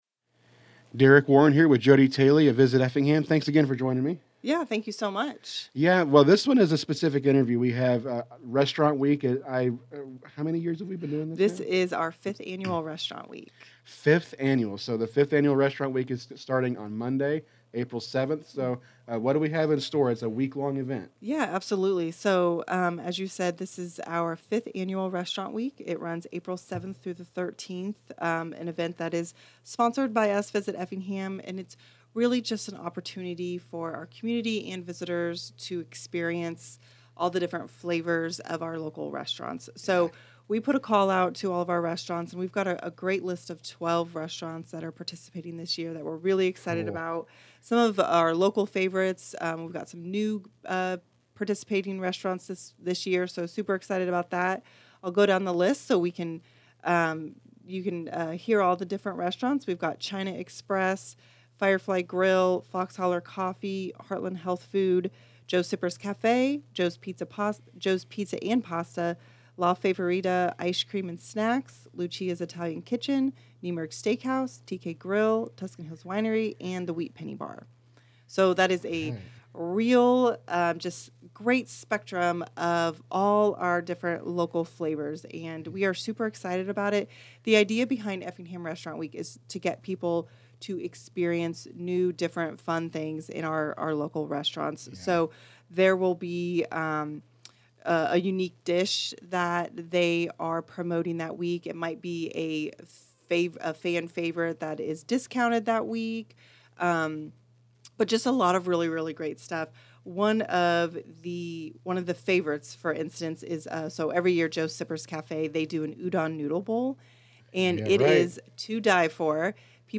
stopped by the studio
This interview